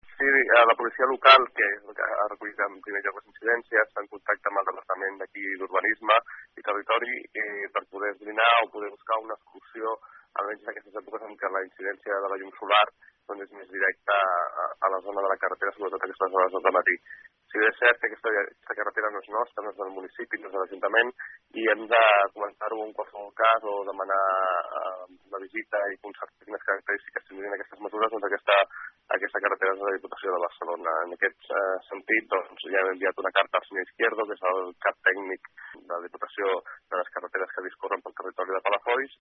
Precisament en aquest sentit el consistori demanarà a la Diputació de Barcelona la instal·lació d’uns panells mòbils que facin ombra i redueixin el perill. Ho explica el Regidor d’Urbanisme de l’Ajuntament, Josep Rueda.